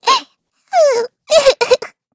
Taily-Vox_Sad_kr.wav